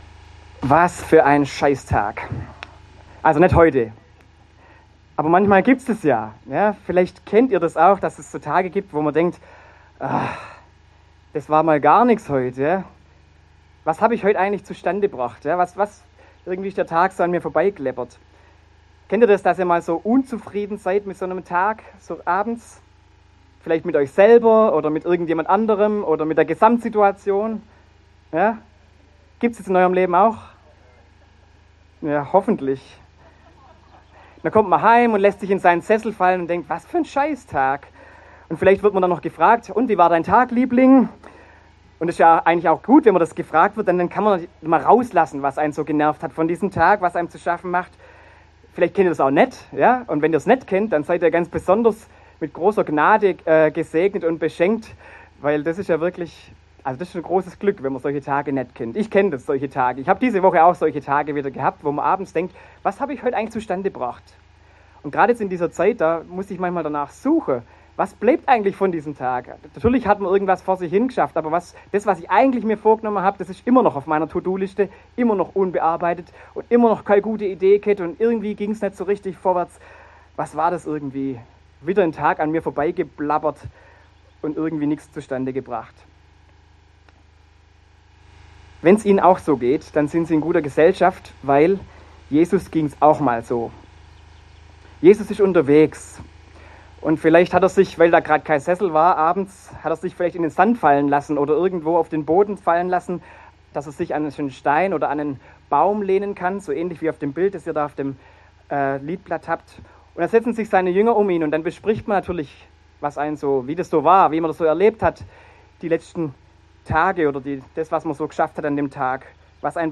Predigt
im Gottesdienst im Grünen am 2. Sonntag nach Trinitatis